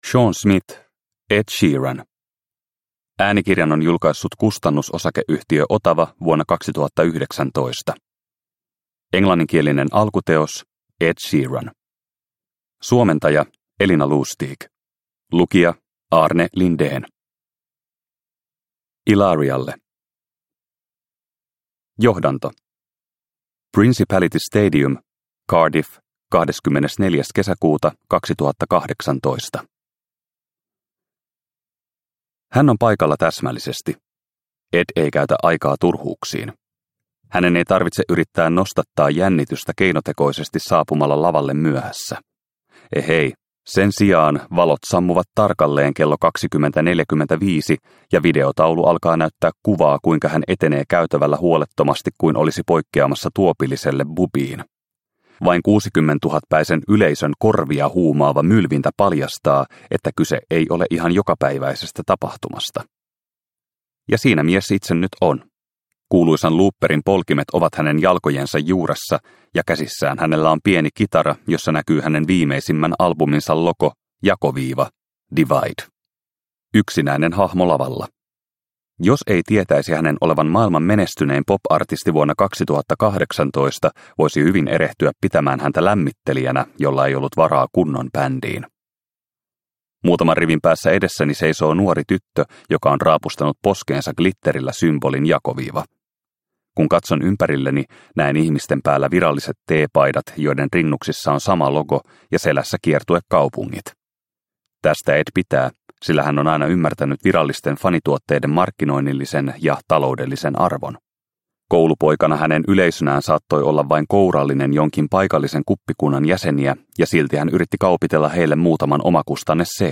Ed Sheeran – Ljudbok – Laddas ner